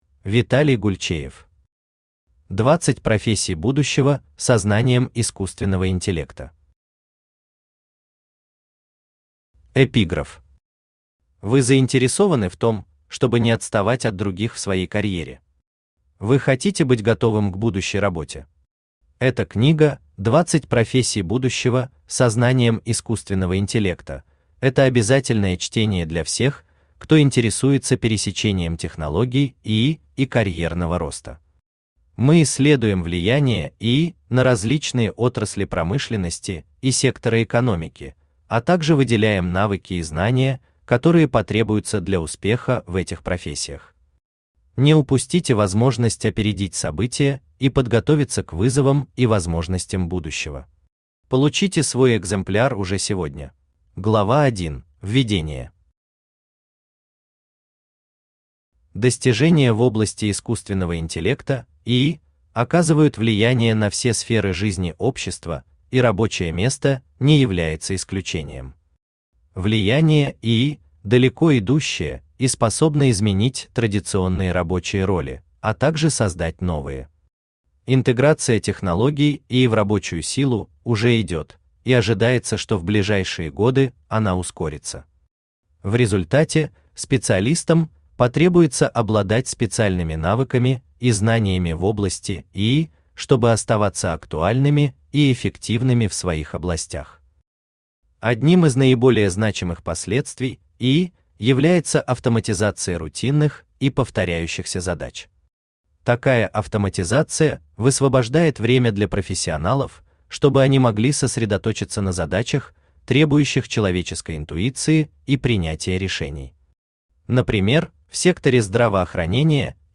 Аудиокнига 20 профессий будущего со знанием искусственного интеллекта | Библиотека аудиокниг
Читает аудиокнигу Авточтец ЛитРес.